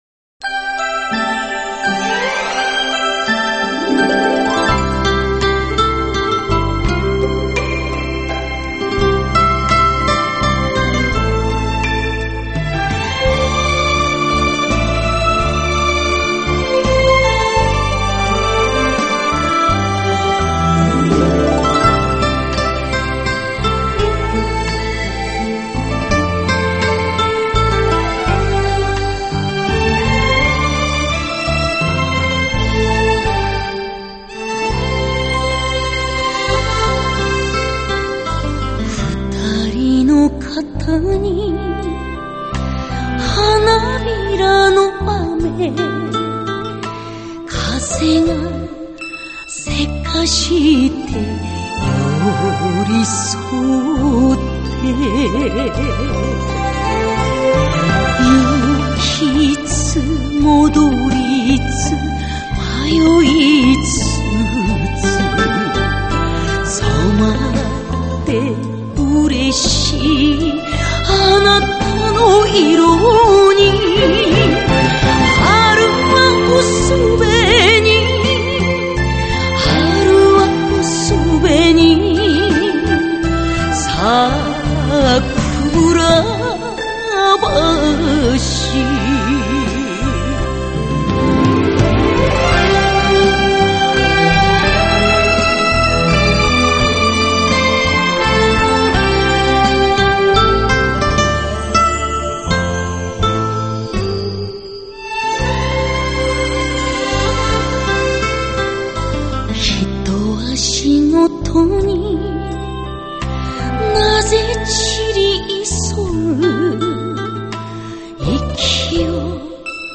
櫻の情景をメジャーワルツに乗せて歌う
彼女の暖かくて柔らかい、新境地ともいえる歌唱をご堪能あれ。